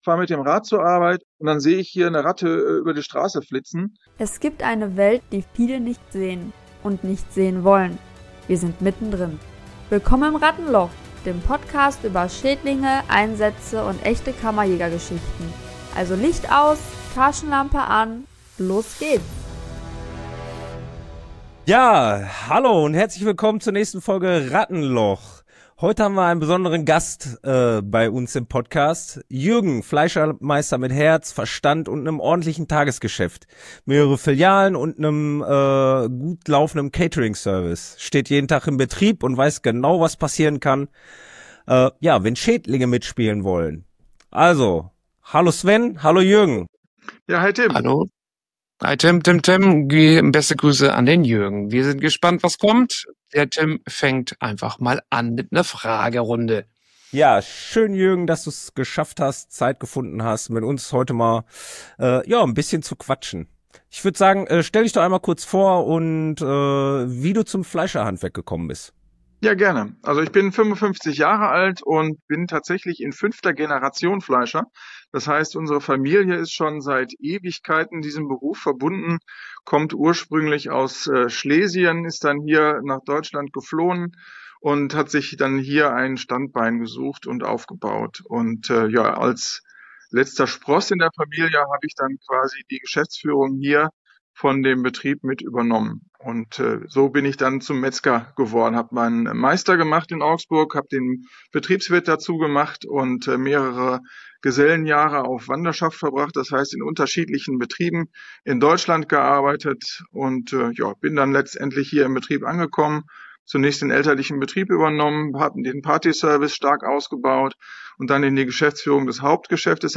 Es geht um echte Herausforderungen in der Lebensmittelbranche, transparente Dokumentation, Behördenkontrollen und den Unterschied zwischen reaktiver Bekämpfung und vorausschauender Vorsorge. Ein ehrliches Gespräch über Verantwortung, Vertrauen und Teamarbeit mit dem Kammerjäger.